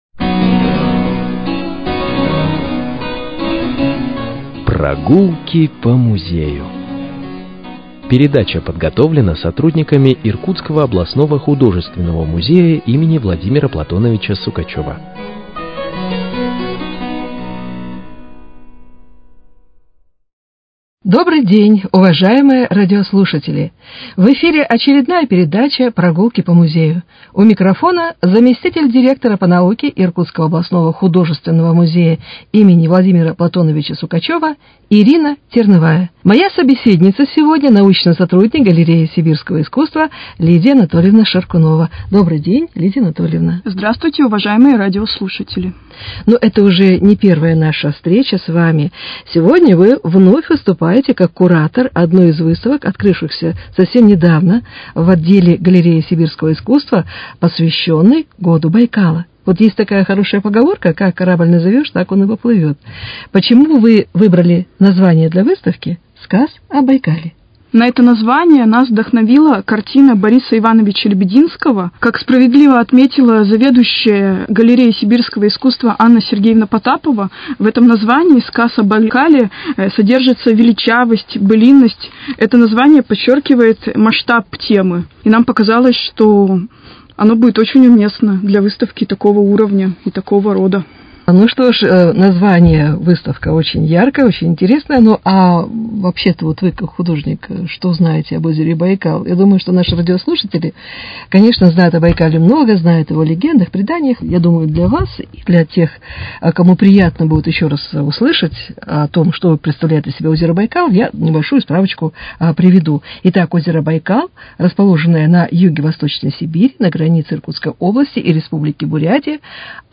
Передача